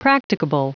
Prononciation du mot practicable en anglais (fichier audio)
Prononciation du mot : practicable